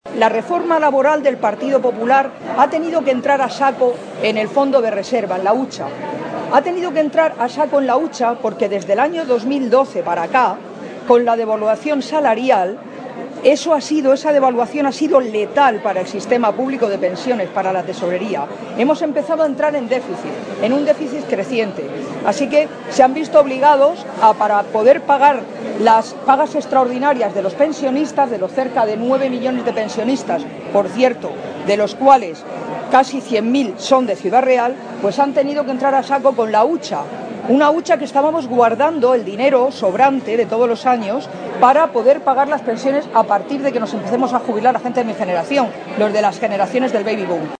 En acto público en Puertollano
Cortes de audio de la rueda de prensa